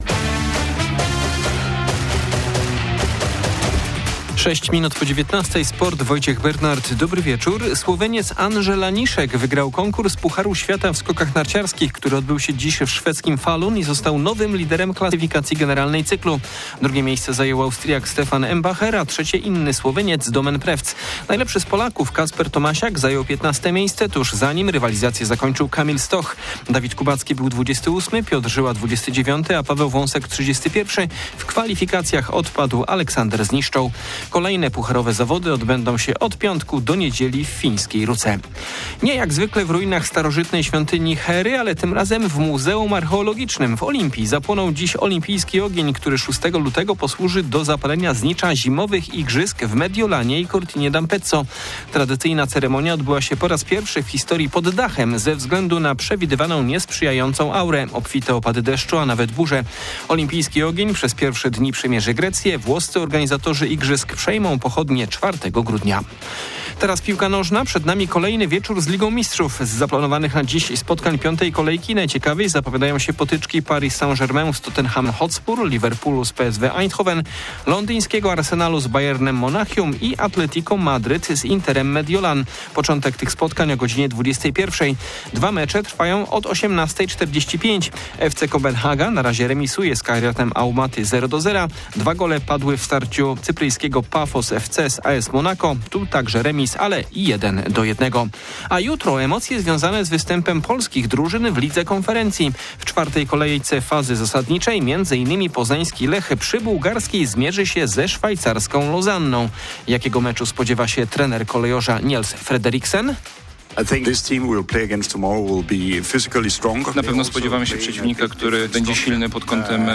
26.11.2025 SERWIS SPORTOWY GODZ. 19:05